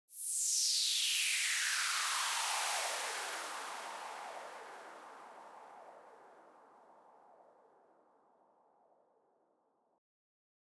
MB Trans FX (10).wav